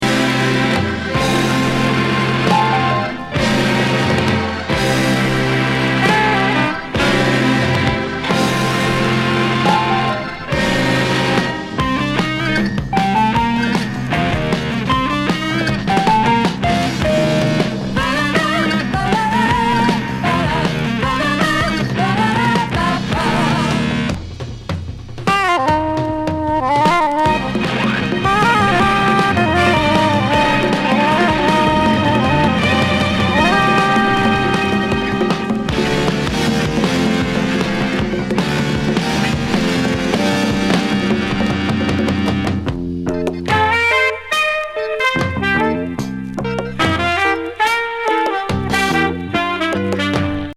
ハードなギターが暴れまわるモンド？